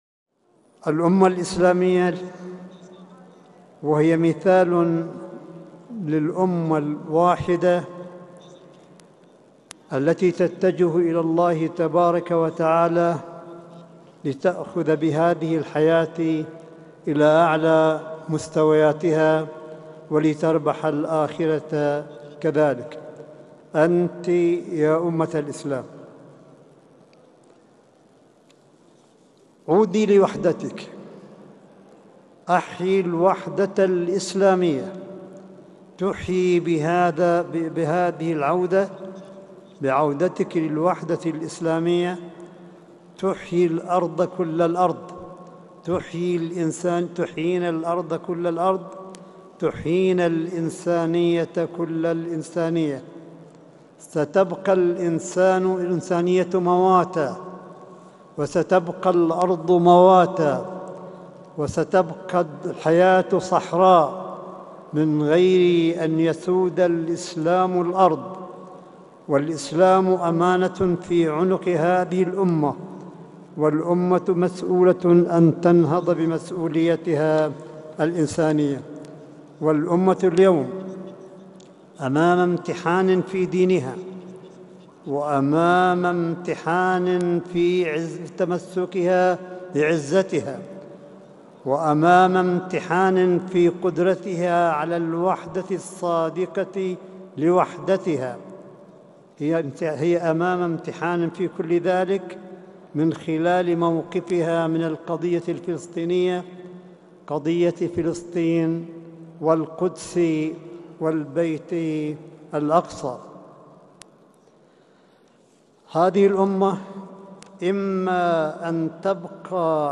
ملف صوتي لكلمة سماحة آية الله الشيخ عيسى أحمد قاسم في الؤتمر الدولي 33 للوحدة الإسلامية في طهران والذي ينظمه المجمع العالمي للتقريب بين المذاهب – 14 نوفمبر 2019م